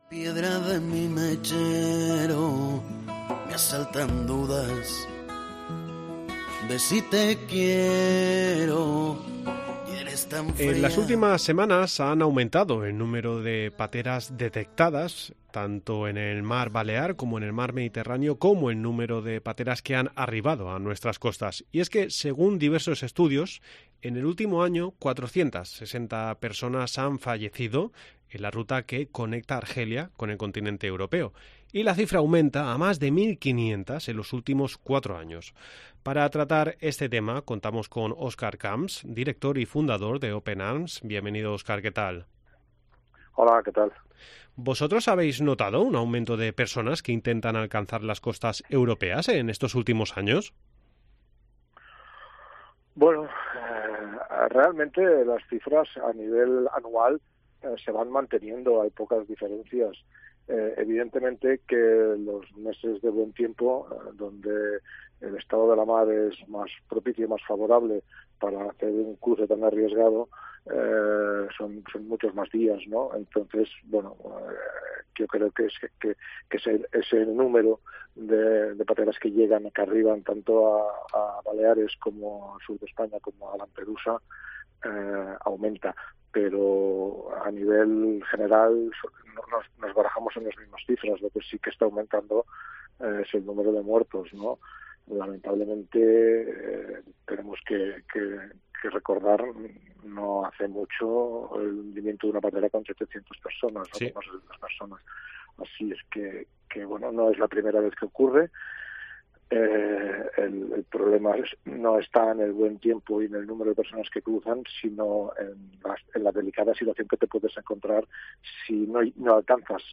AUDIO: Contactamos con Oscar Camps, fundador y director de la embarcación Open Arms, para conocer la situación actual del Mediterráneo.
Para tratar este tema contamos con Oscar Camps, director y fundador de Open Arms